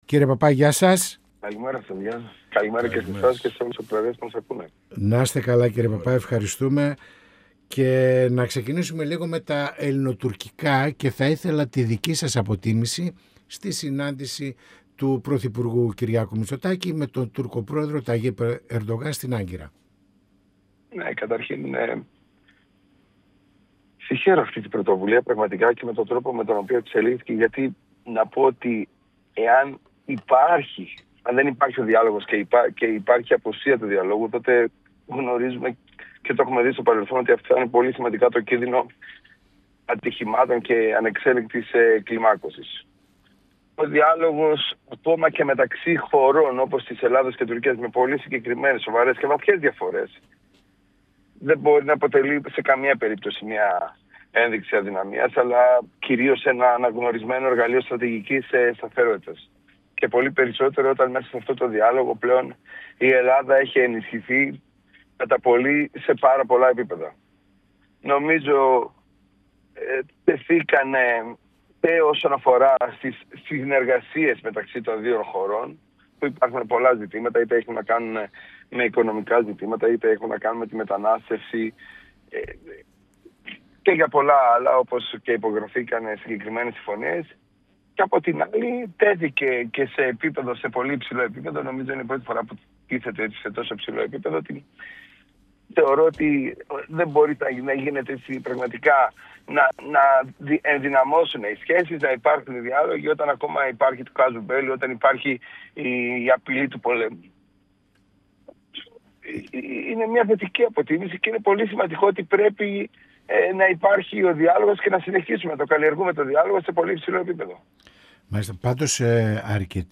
Ο Βουλευτής της ΝΔ Θεοφάνης Παπάς, στον 102FM | «Πανόραμα Επικαιρότητας» | 14.02.2026
Στην αξιολόγηση των αποτελεσμάτων της συνάντησης – επίσκεψης του πρωθυπουργού Κυριάκου Μητσοτάκη με τον Τούρκο Πρόεδρο Ταγίπ Ερντογάν στην Άγκυρα, καθώς και τη πρόσφατη κινητοποίηση των Αγροτών στην Αθήνα αναφέρθηκε ο Βουλευτής της ΝΔ Θεοφάνης Παπάς, μιλώντας στην εκπομπή «Πανόραμα Επικαιρότητας» του 102FM της  ΕΡΤ3.